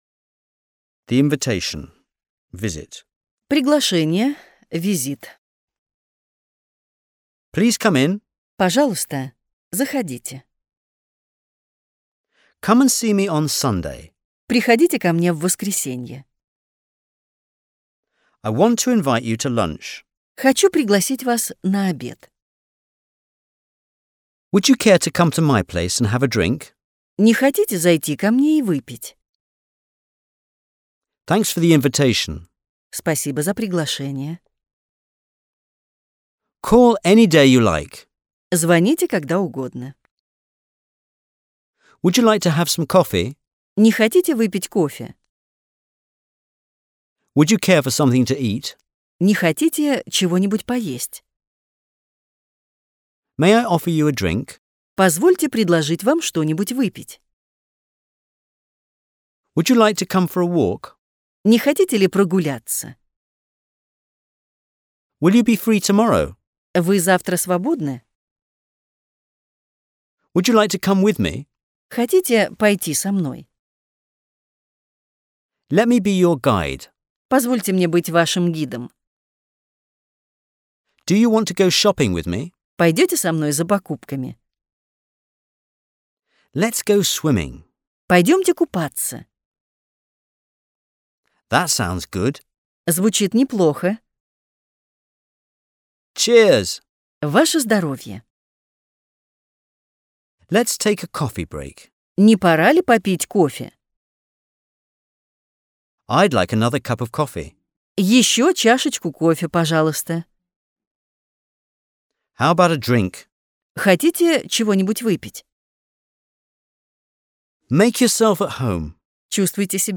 Аудиокурс английский язык за 12 дней — часть 10